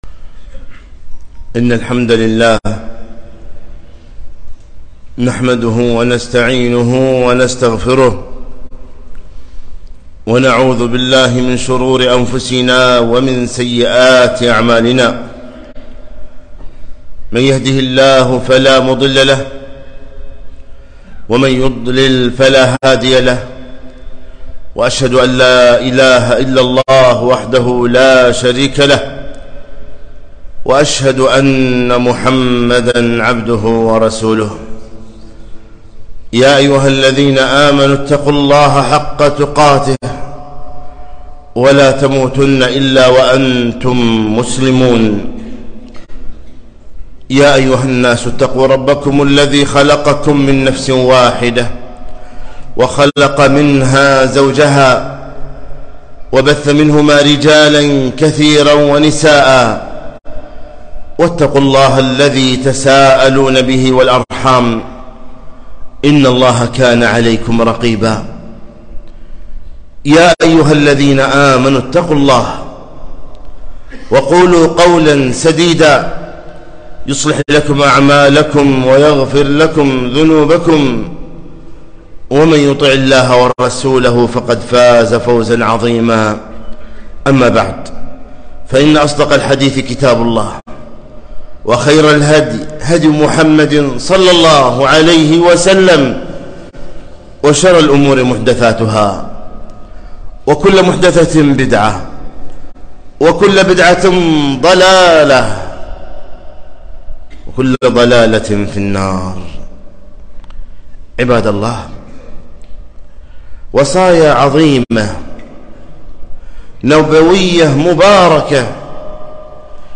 خطبة - هل تريد أن تكون أغنى الناس؟